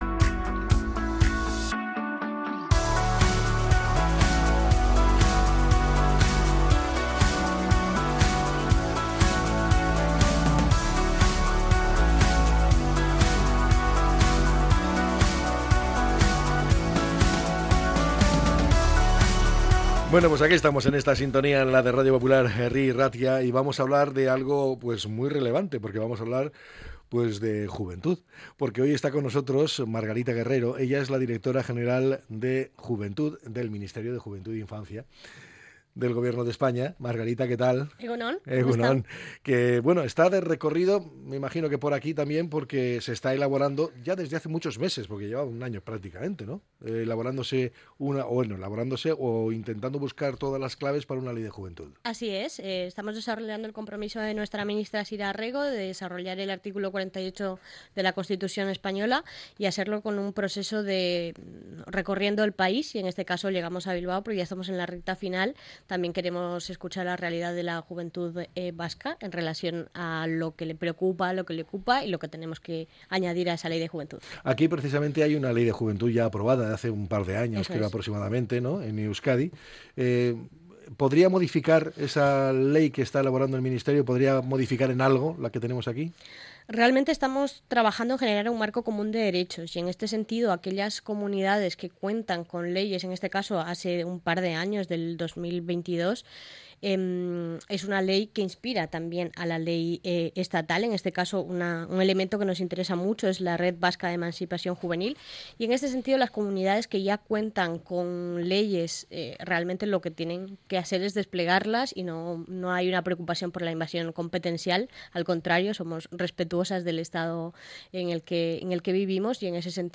Entrevista con la Directora General del Instituto de la Juventud en el marco de la elaboración de la futura Ley de Juventud